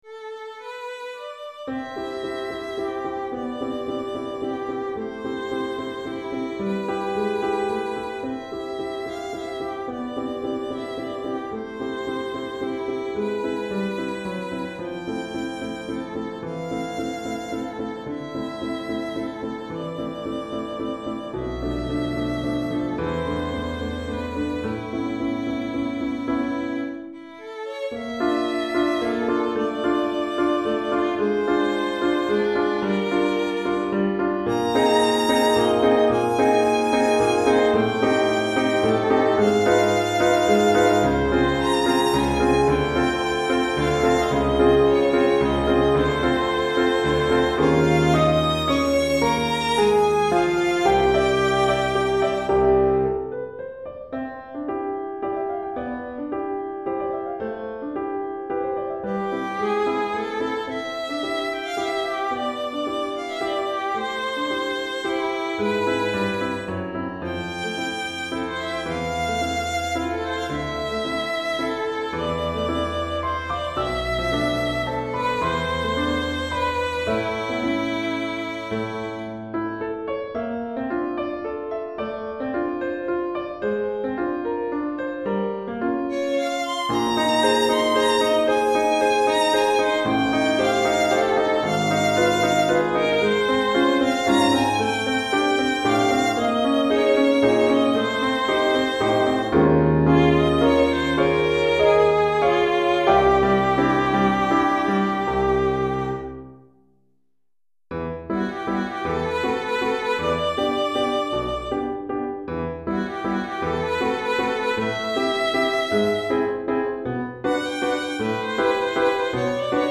Violon et Piano